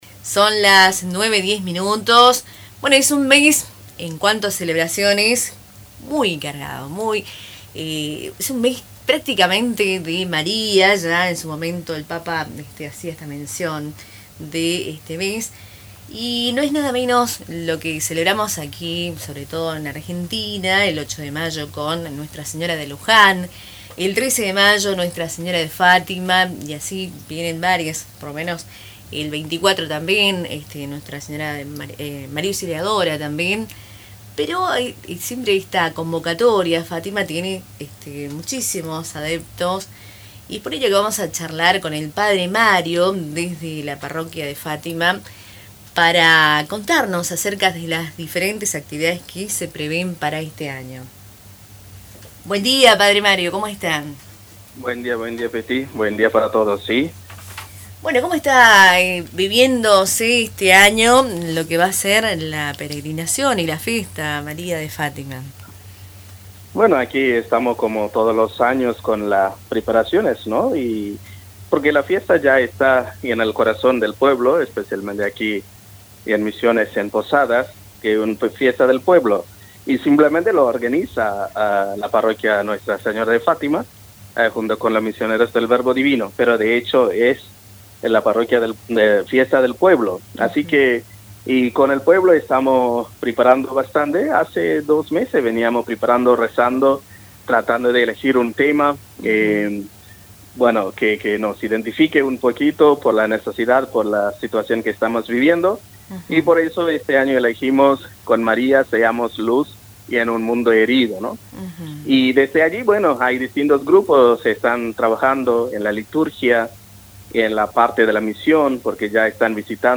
En una conversación telefónica desde la Parroquia de Fátima con Radio Tupa Mbae